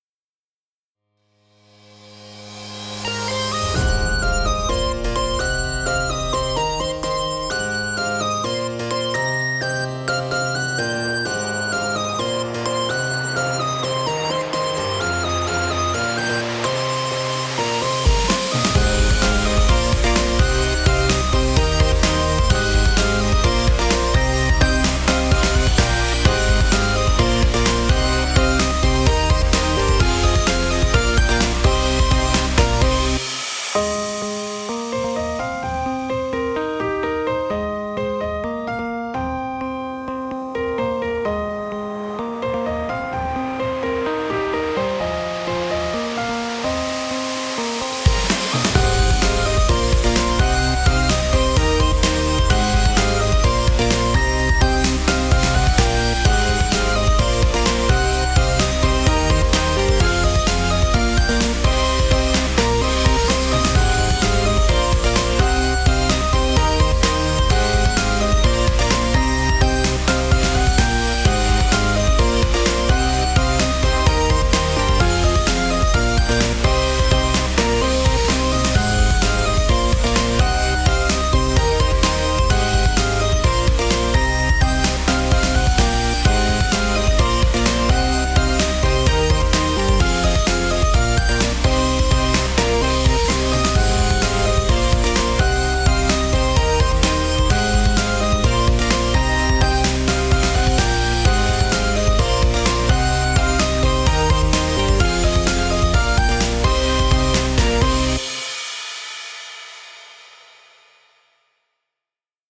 【耐久版あり】リリースカットピアノを主軸としたクールなBGMですオープニングやかっこいいシーンにどうぞ！